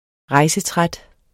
Udtale [ ˈʁɑjsəˌtʁad ]